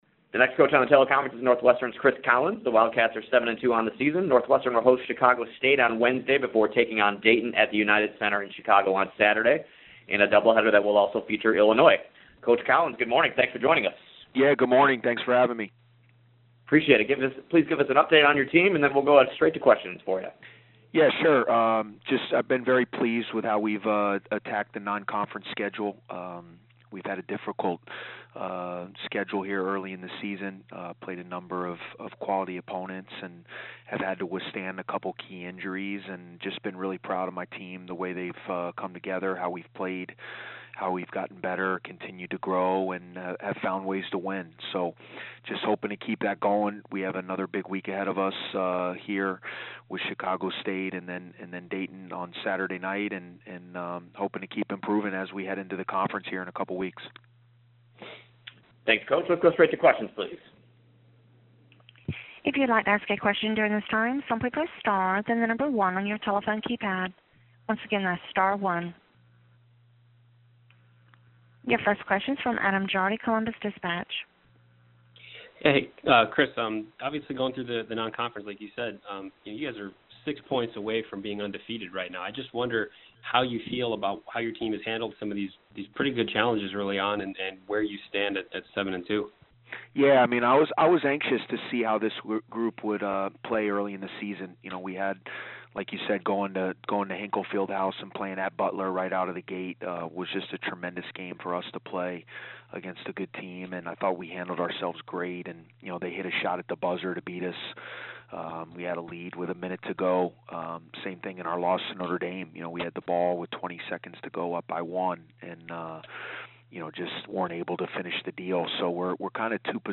On Monday, the Big Ten men's basketball coaches participated in a teleconference to take questions from the media.